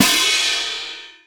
BECKEN     1.wav